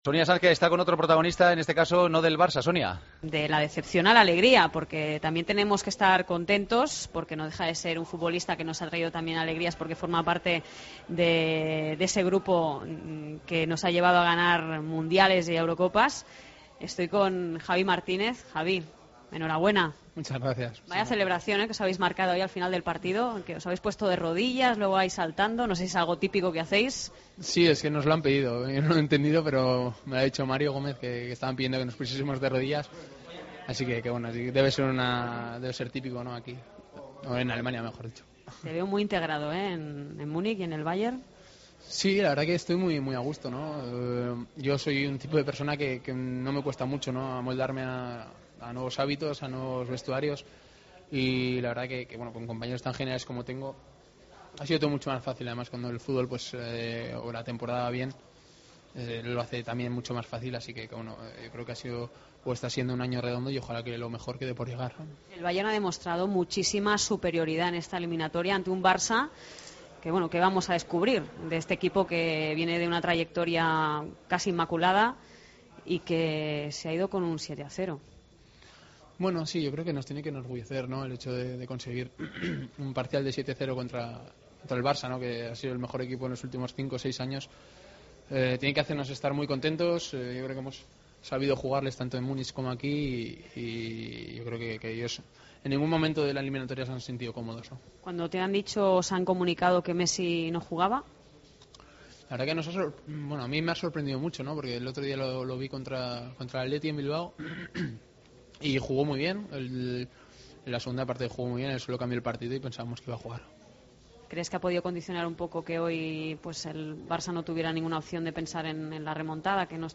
Entrevista a Javi Martínez: "La final alemana será como un Madrid-Barça"